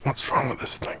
WELDER-IDLE2.mp3